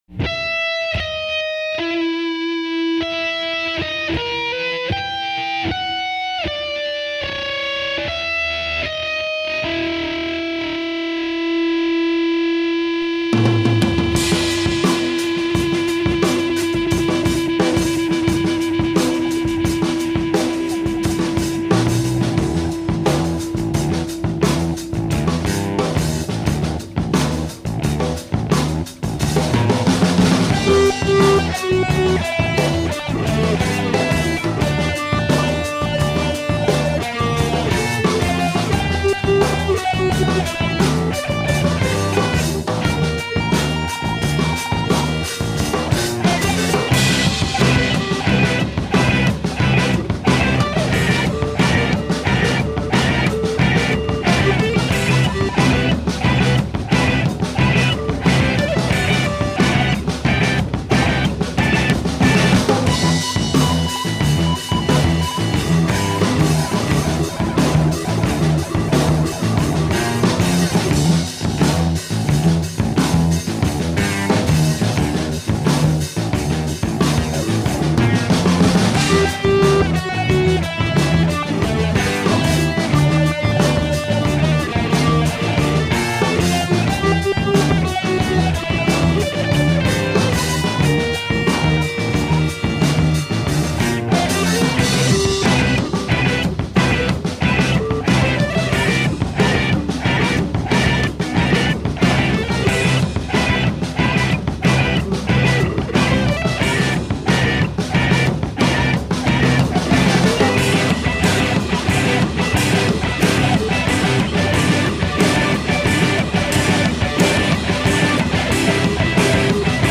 Recorded on: Tascam 424mkIII
I went back to using just the 4-track, but with the extra added help of a dumb, cheap compressor I dug out of the basement for the first time in years.
Wow - it almost sounds like I know how to play the saxophone.